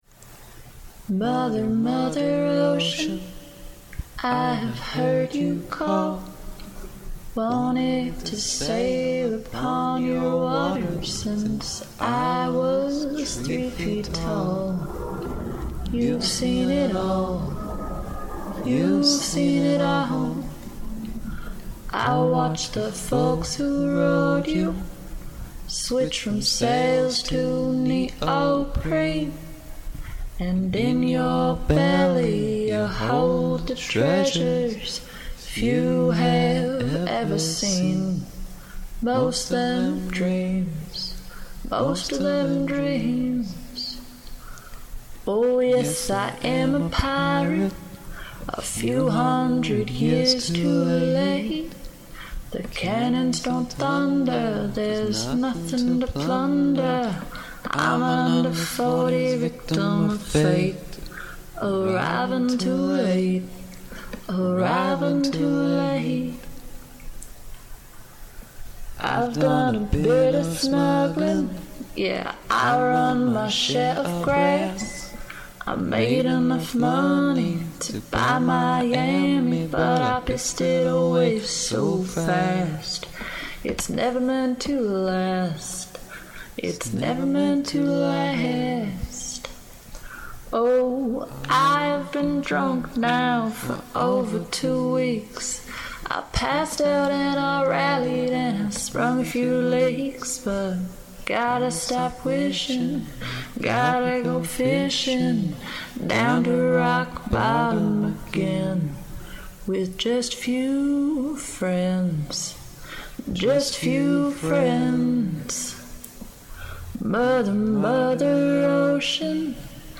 Cross Atlantic duet